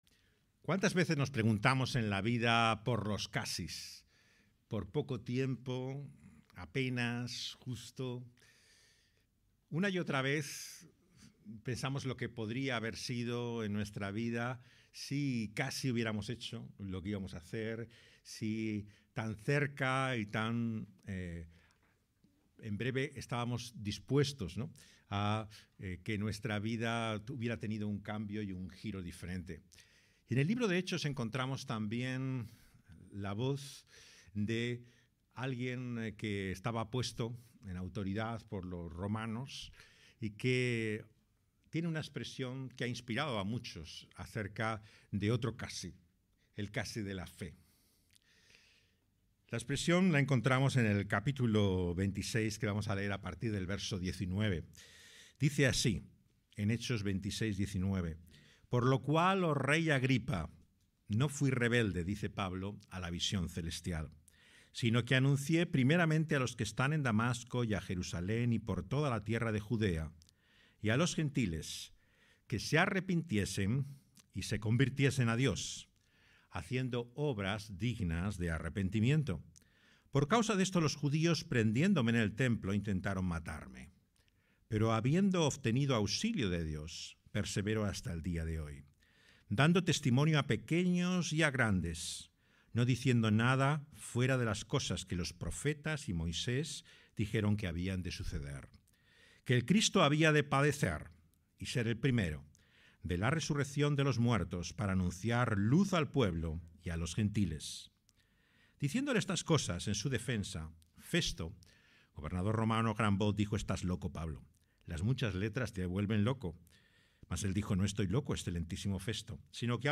grabado en Madrid